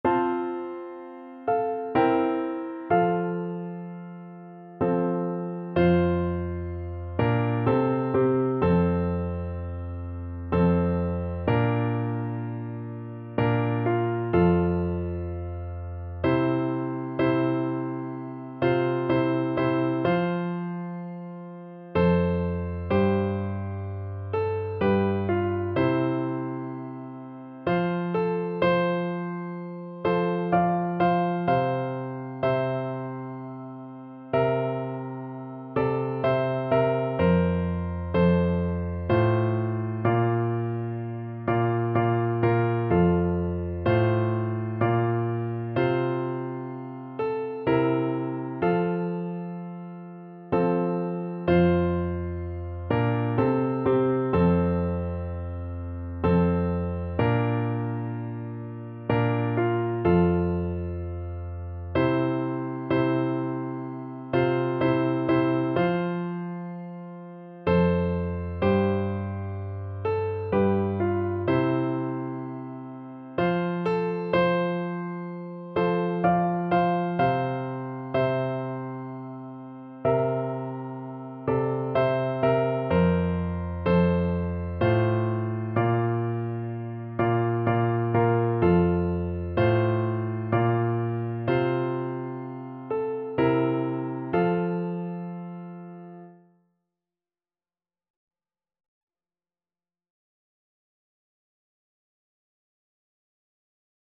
3/4 (View more 3/4 Music)